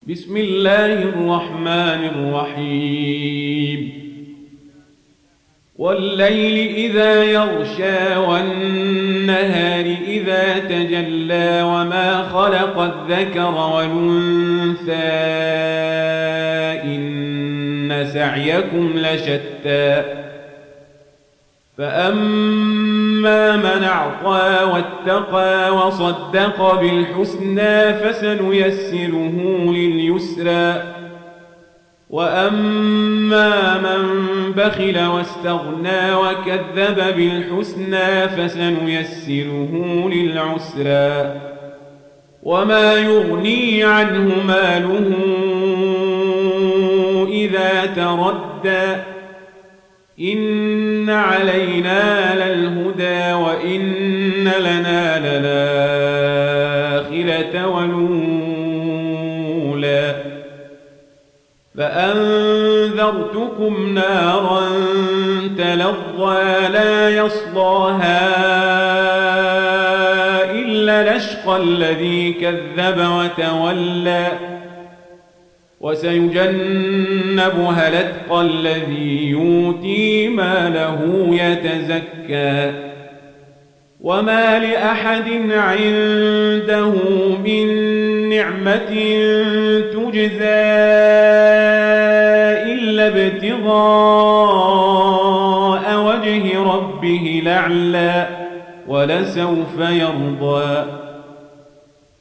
Riwayat Warsh